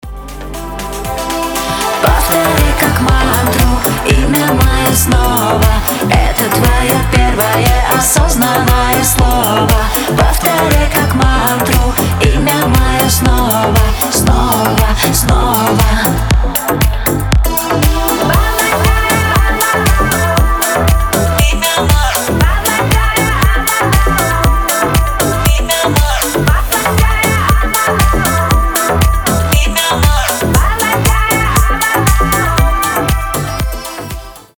• Качество: 320, Stereo
поп
громкие
женский вокал
чувственные
индийские мотивы